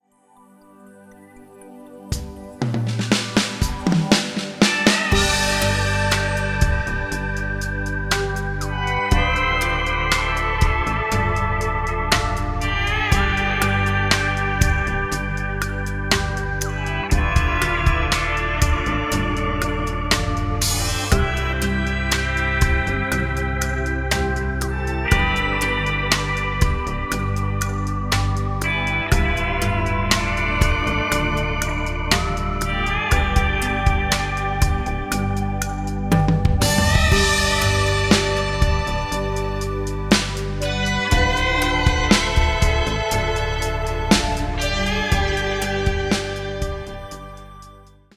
Genre: Meditation